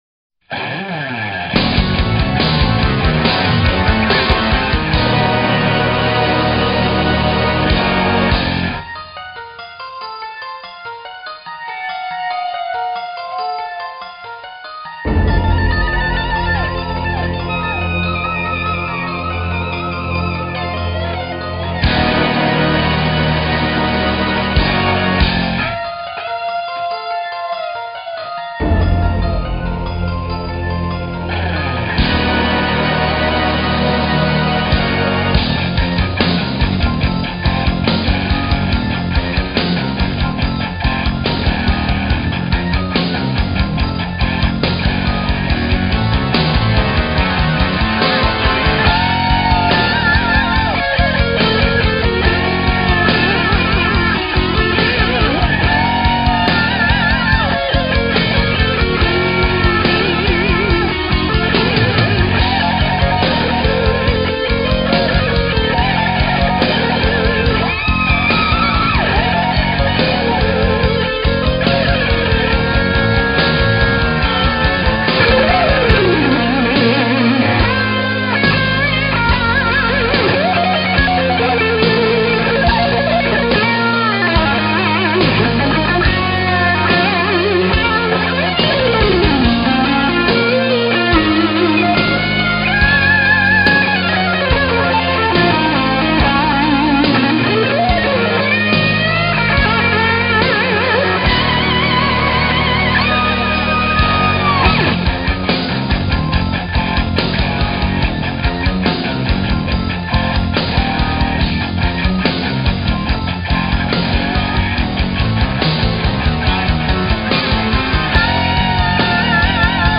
0274-吉他名曲永恒.mp3